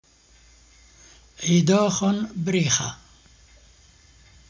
Merry Christmas greeting to a group of people.